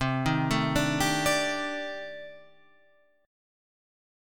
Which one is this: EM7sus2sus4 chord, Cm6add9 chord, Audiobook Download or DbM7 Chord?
Cm6add9 chord